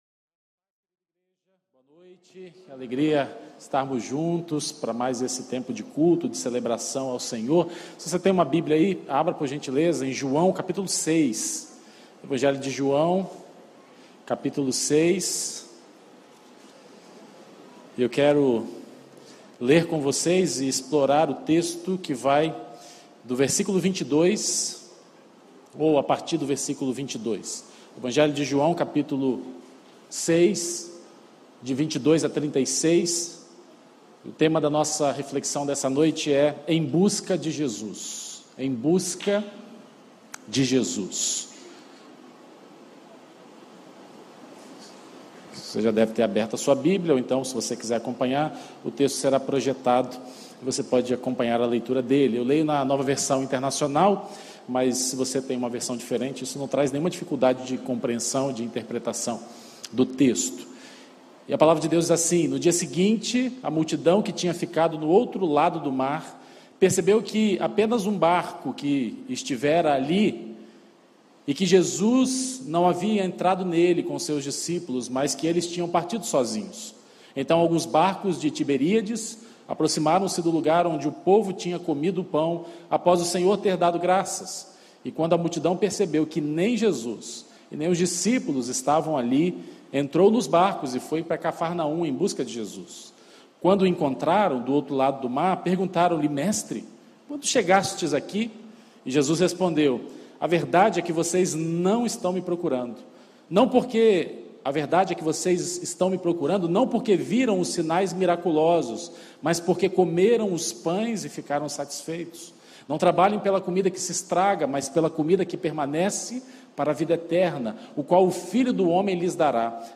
Mensagem apresentada